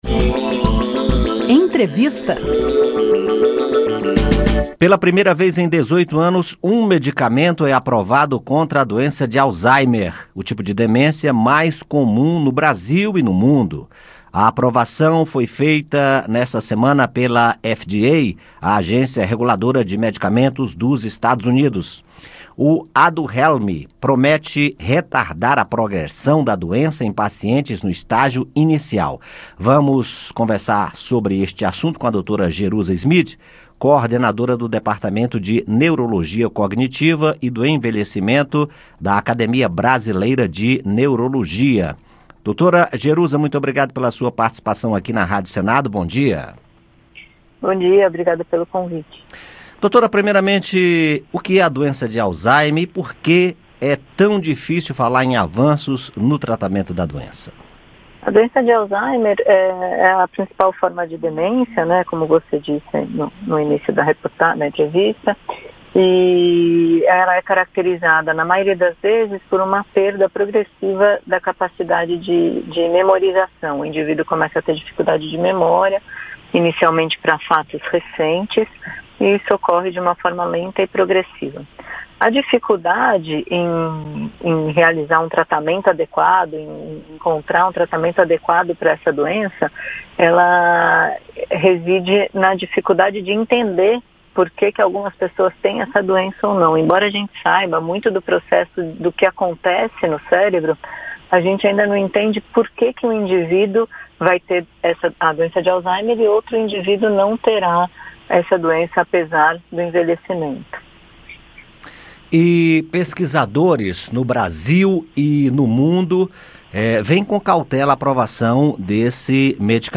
O medicamento foi aprovado na segunda-feira (7) pela FDA, agência reguladora de medicamentos dos EUA, mas tem gerado polêmica entre especialistas, pois ainda não há comprovação de sua eficácia. Ouça a entrevista.